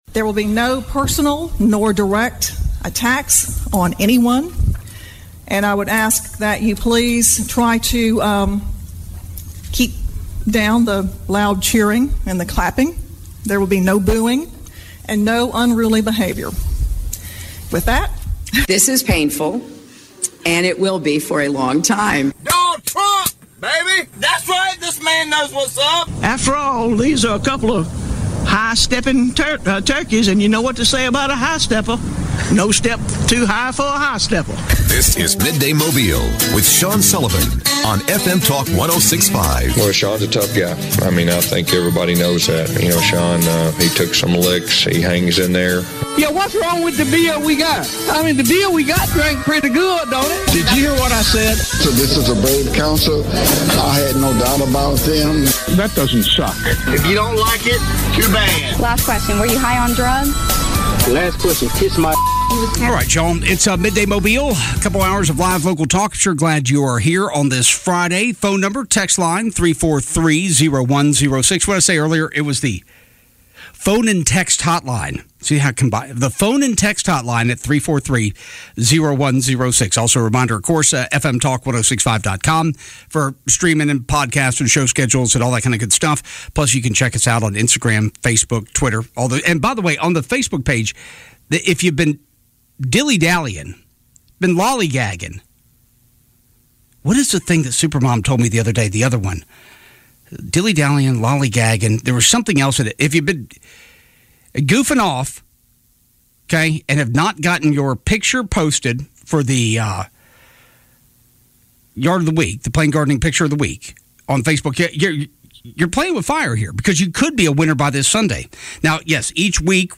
State Rep. Chip Brown joins the show.